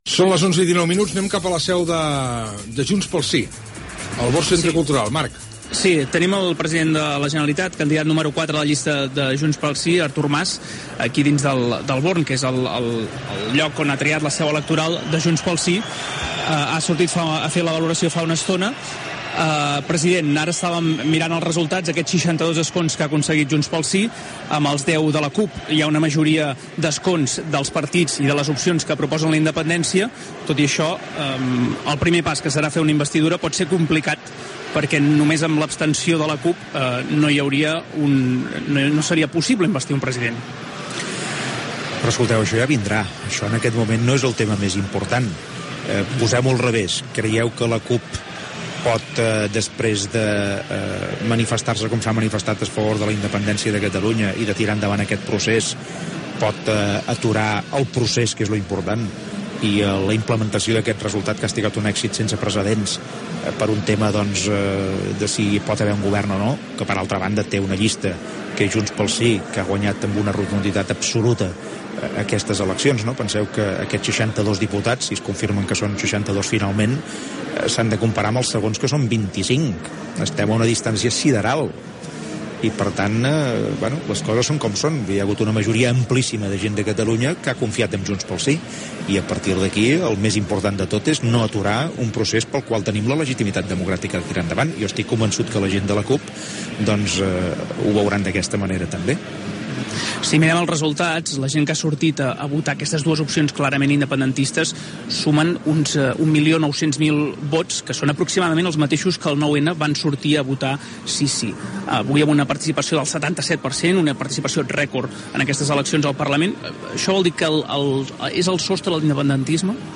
Fragment d'una entrevista al polític Artur Mas sobre el resultat de les eleccions al parlament de Catalunya del 27 de setembre de 2015. Valoració dels resultats de Junts pel Sí
Informatiu